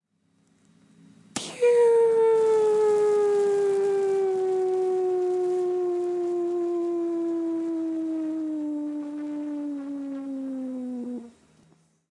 描述：用声音制造。改变音调听起来像卡通片中的摔倒
Tag: 卡通 动画 下降